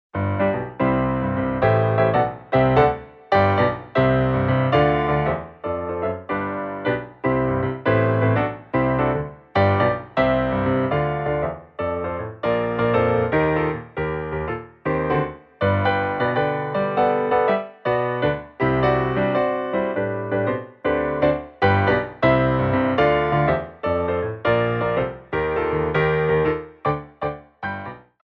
QUICK TEMPO